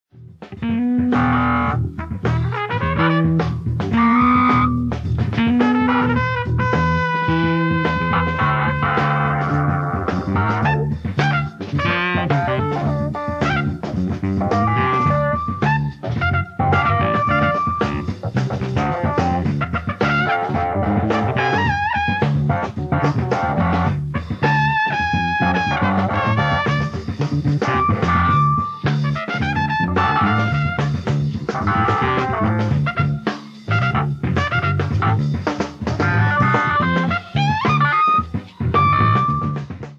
LIVE AT FILLMORE WEST, SAN FRANCISCO 04/09/1970
SOUNDBOARD RECORDING